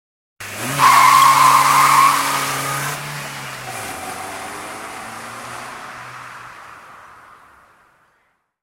Визг шин – альтернативный вариант